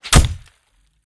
knife_hitwall3.wav